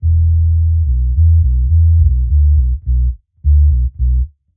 Index of /musicradar/dub-designer-samples/105bpm/Bass
DD_JBass_105_E.wav